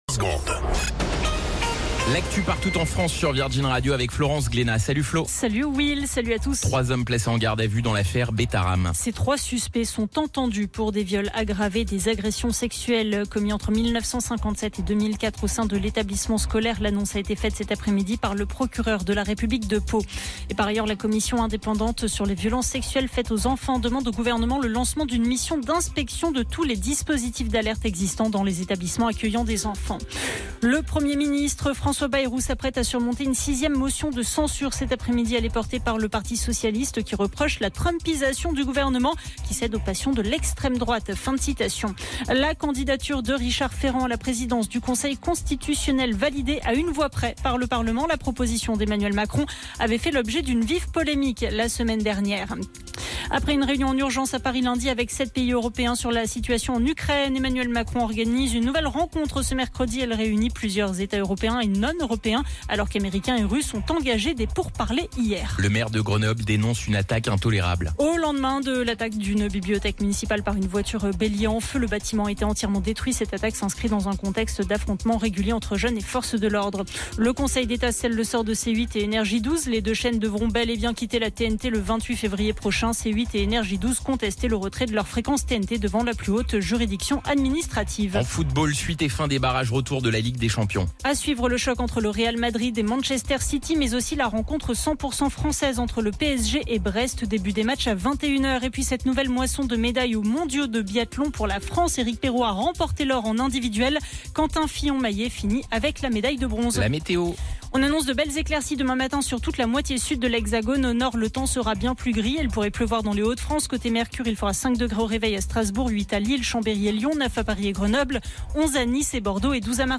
Flash Info National 19 Février 2025 Du 19/02/2025 à 17h10 .